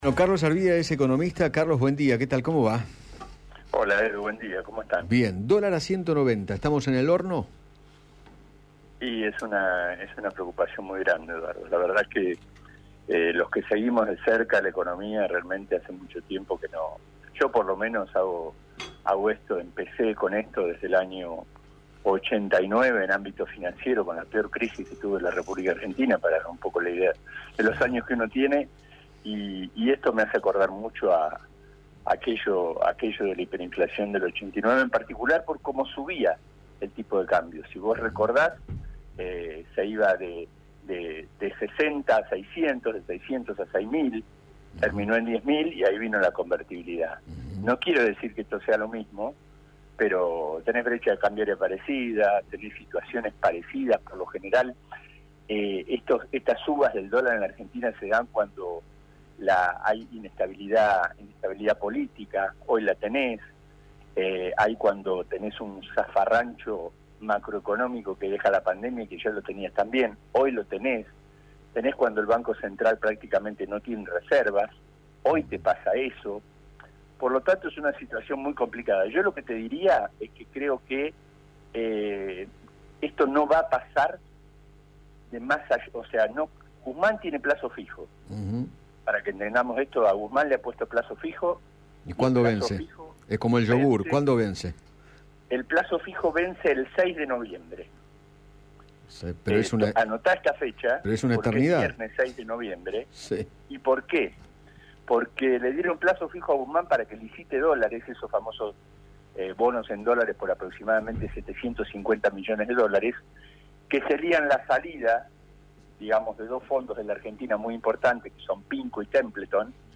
economista y periodista